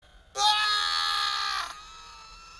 Woody Evil Laugh Sound Button - Free Download & Play